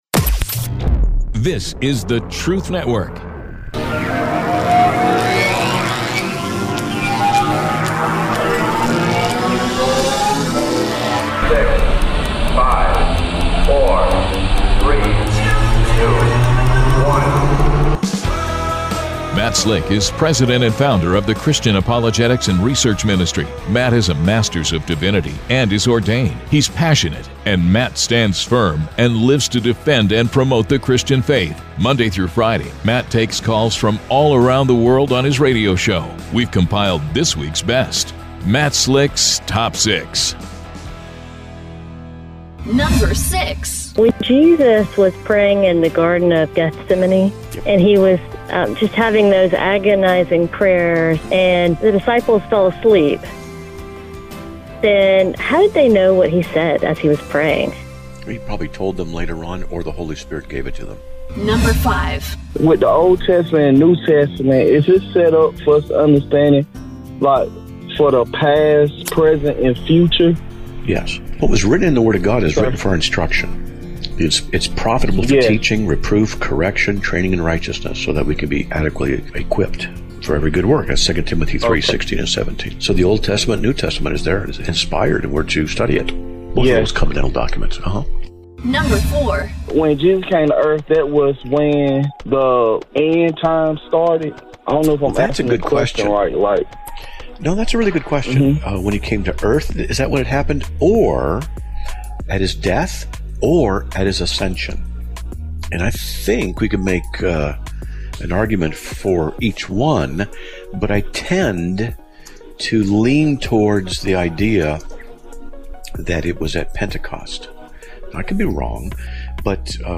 The sound bites are from Roy Rogers